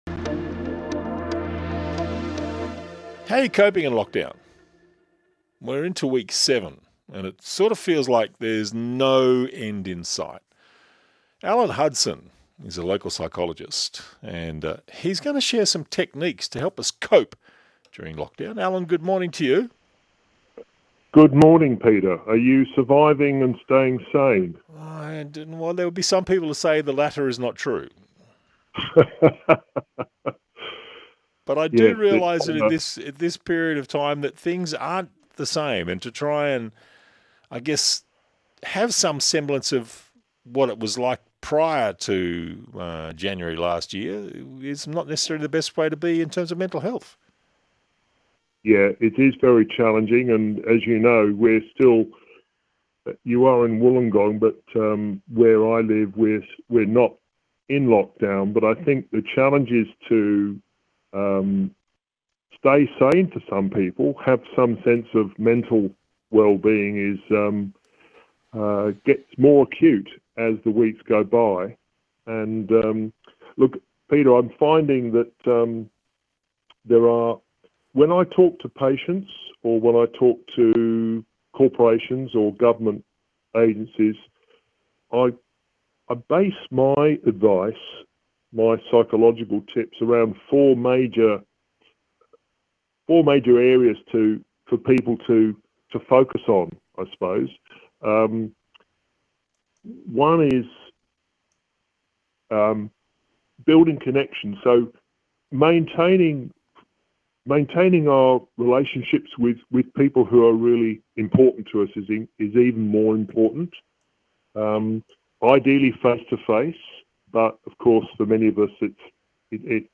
How to build psychological resilience during the pandemic – ABC Interview
ABC-interview-Building-Psychological-Resilience-in-Lockdown-.mp3